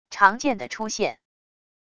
长剑的出现wav音频